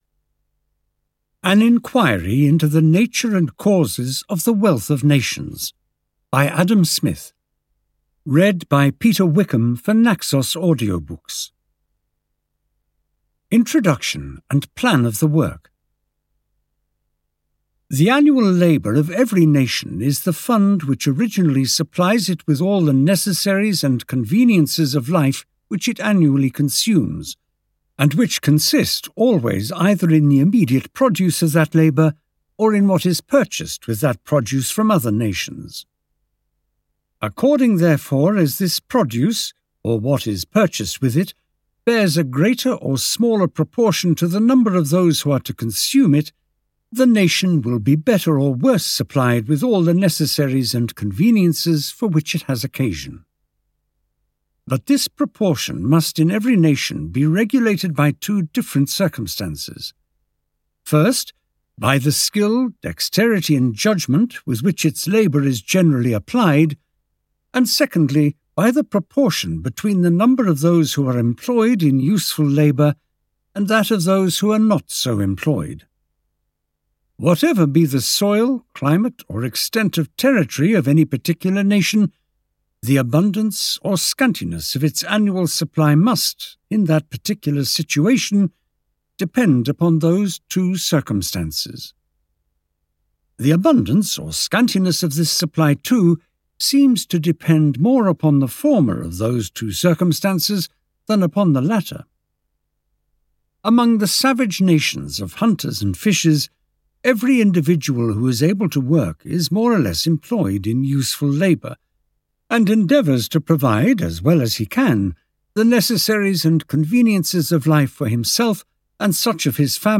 The Wealth of Nations (EN) audiokniha
Ukázka z knihy